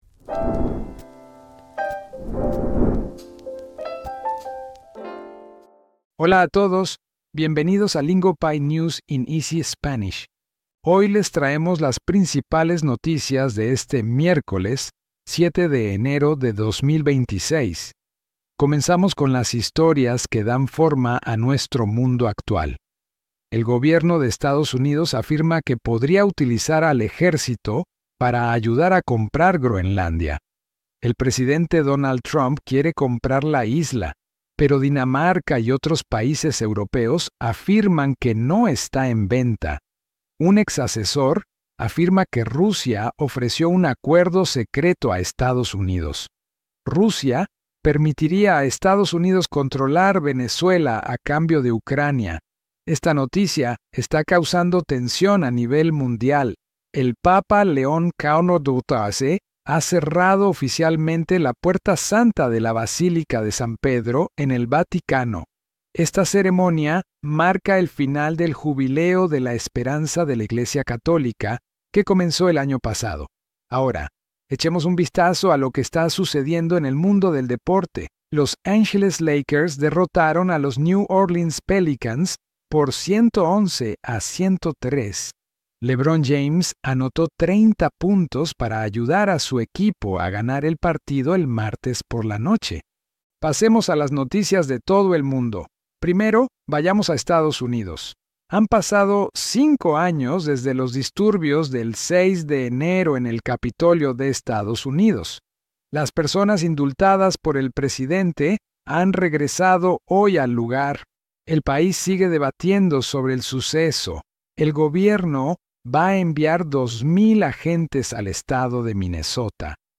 Lingopie's News in Easy Spanish meets you exactly where you are, covering today's biggest global stories in clear, beginner-friendly Spanish so you can follow along and actually understand what's going on.